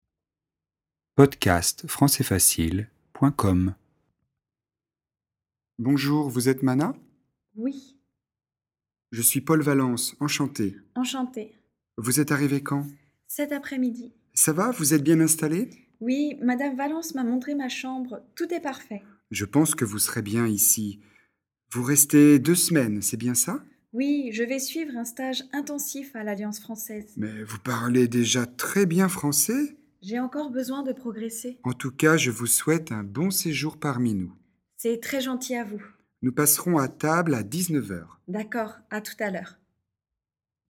Dialogue FLE et exercice de compréhension, niveau débutant (A1) sur le thème "famille d'accueil"